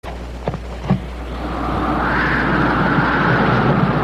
• wind-blowing-whistling.ogg
[wind-blowing-whistling]_sxn.wav